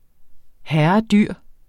Udtale [ ˈhæɐ̯ʌˈ- ]